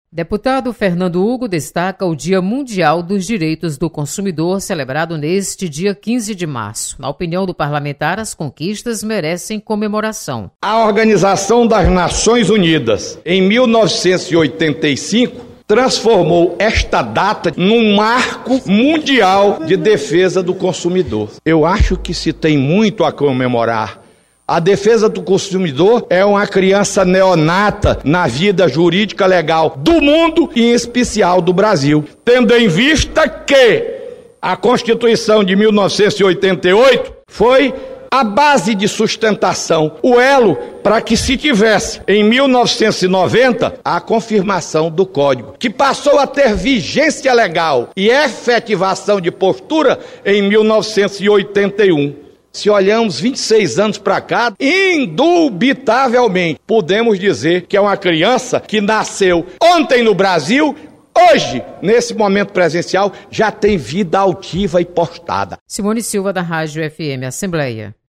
Plenário
Deputado Fernando Hugo destaca Dia Mundial dos Direitos do Consumidor. Repórter